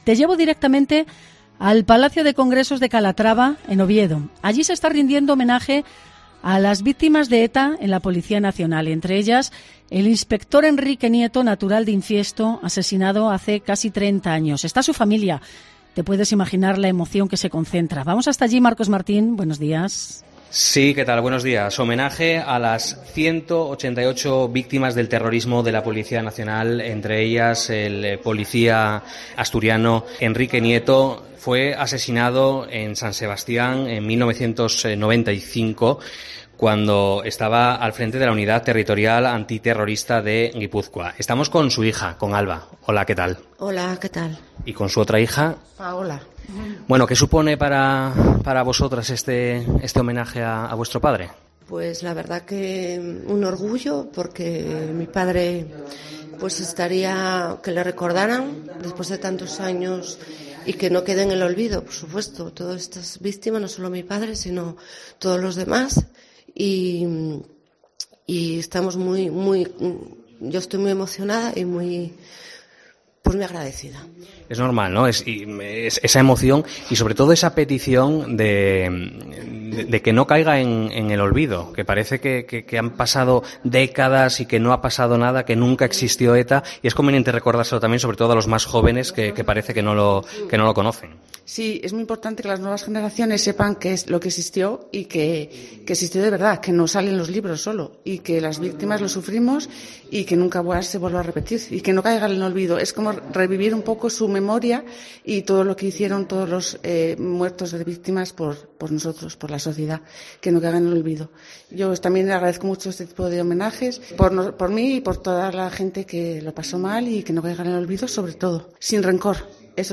Entrevista en COPE